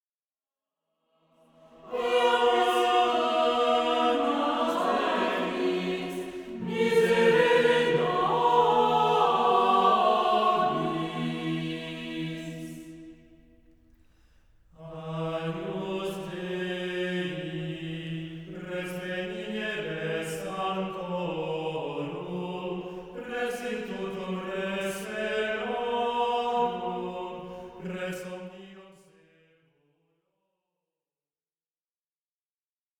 Trope d'Agnus Dei